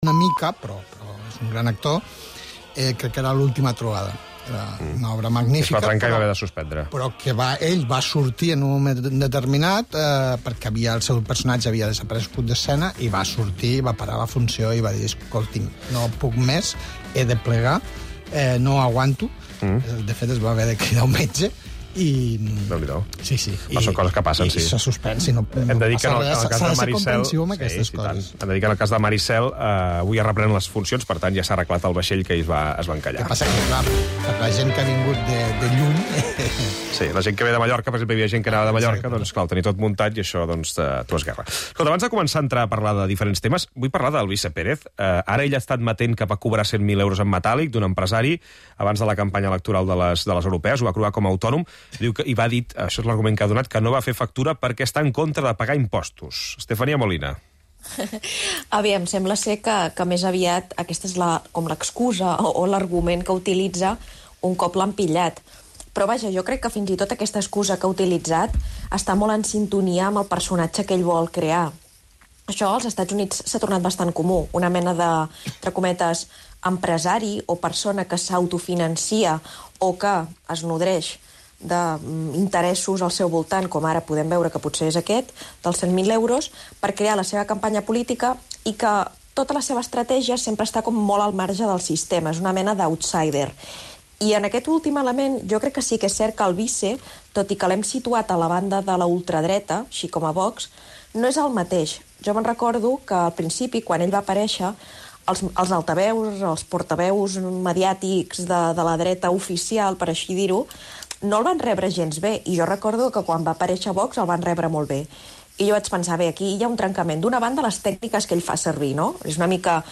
El mat, de 9 a 10 h (tertlia) - 27/09/2024